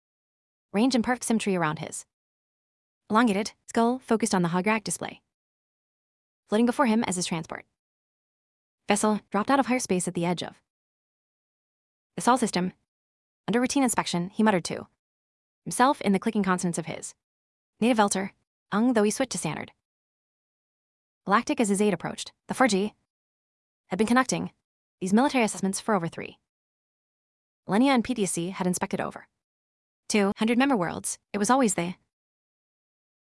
I can’t understand what she’s saying (yep it’s in English :rofl:), way too fast, way too choppy, even in Audacity slowing down to a “normal” pace, it does not look natural.